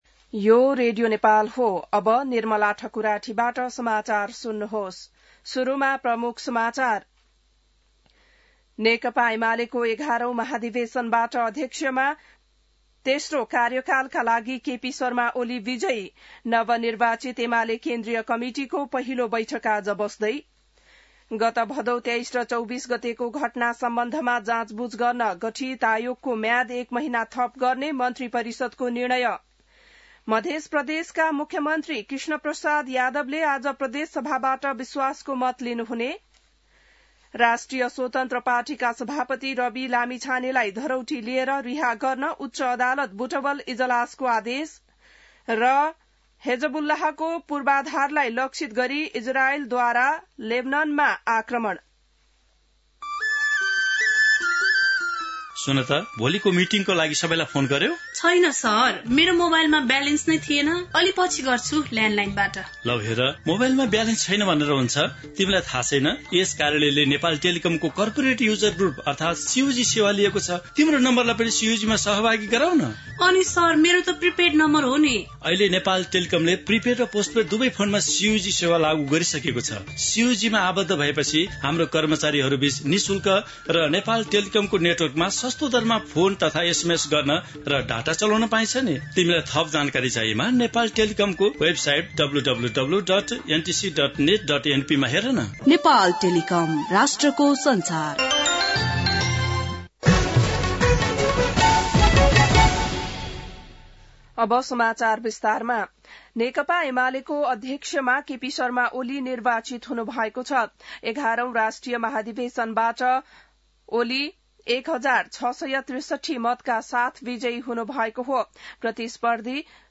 बिहान ७ बजेको नेपाली समाचार : ४ पुष , २०८२